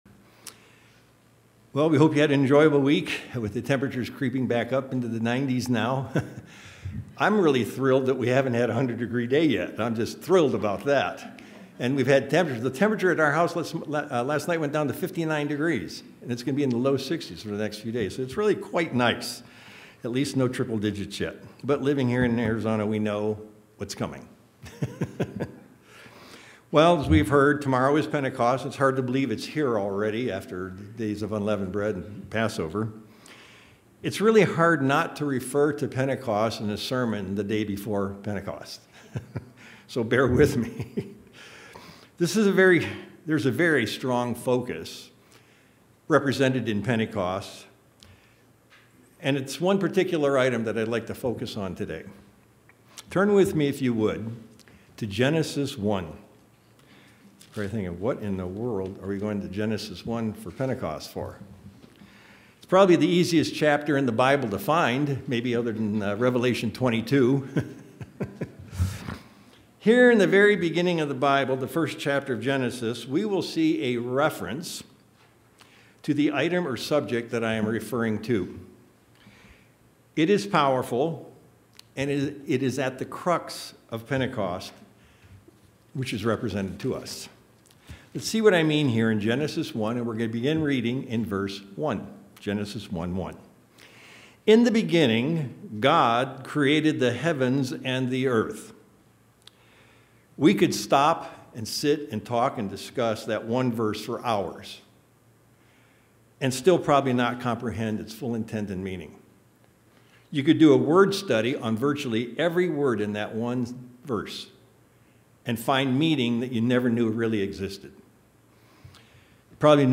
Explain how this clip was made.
How are we to understand the working of the Hol y Spirit? This message was intended as a prelude to the Day of Pentecost.